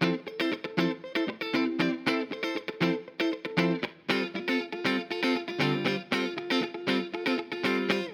28 Guitar PT4.wav